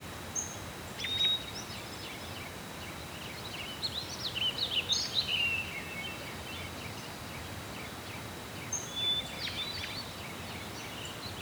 Index of /90_sSampleCDs/E-MU Producer Series Vol. 3 – Hollywood Sound Effects/Ambient Sounds/Outdoor Ambience
FOREST AM01L.wav